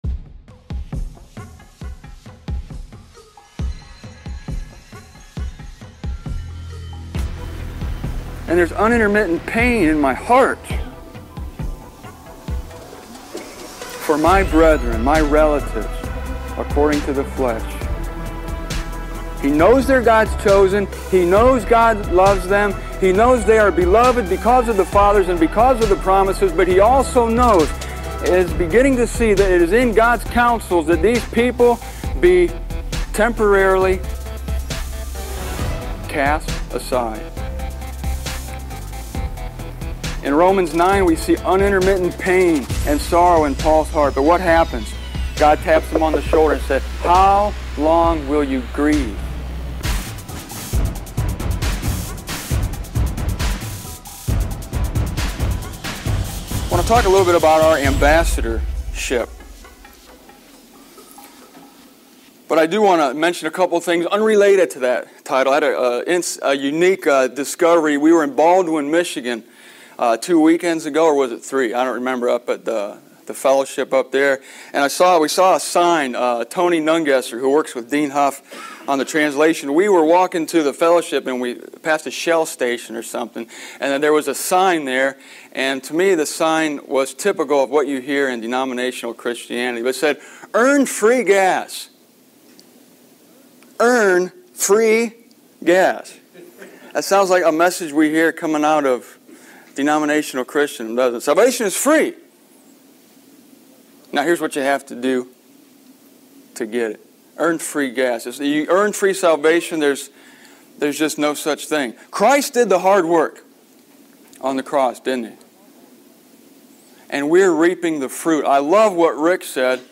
I recorded this audio at the Willard, Ohio conference in June of 1995.